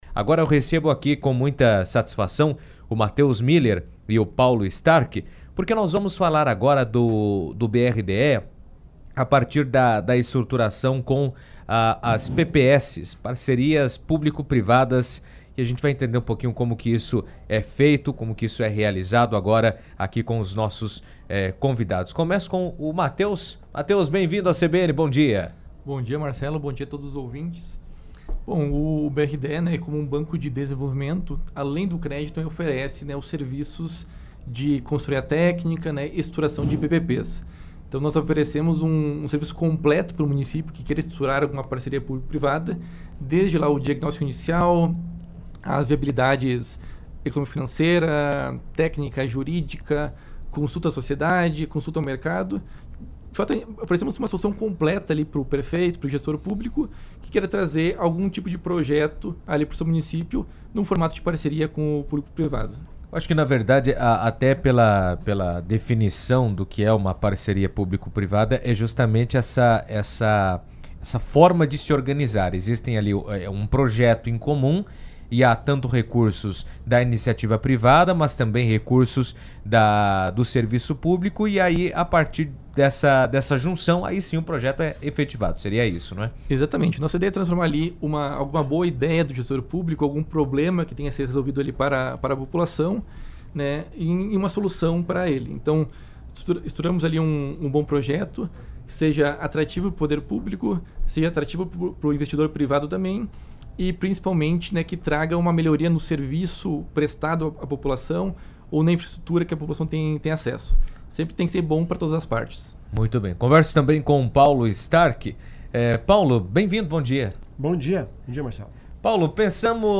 estiveram no estúdio da CBN durante a 38ª edição do Show Rural Coopavel e explicaram como o banco atua na estruturação de parcerias público-privadas (PPPs). Segundo eles, além de fornecer crédito, o BRDE oferece um serviço que se aproxima de consultoria, avaliando projetos e identificando oportunidades para fortalecer a cooperação entre setor público e privado, com potencial de crescimento em diferentes áreas estratégicas do Estado.